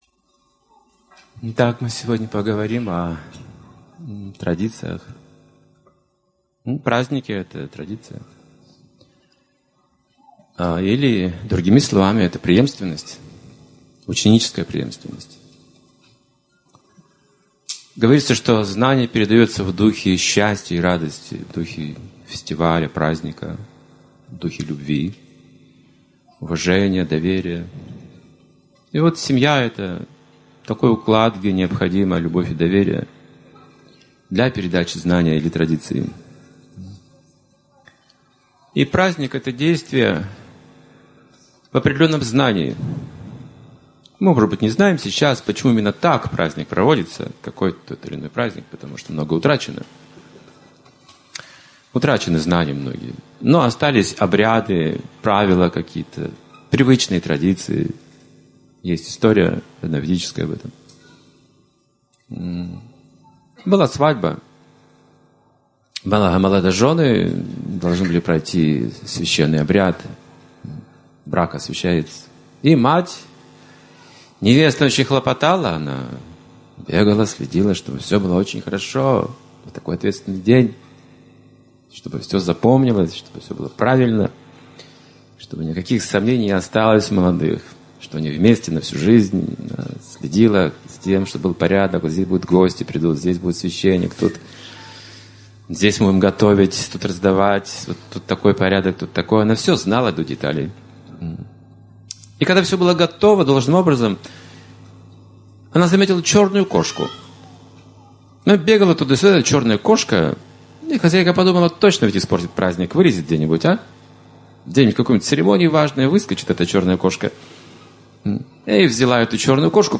Океан счастья, Фестиваль Святоустье (2016, Рига)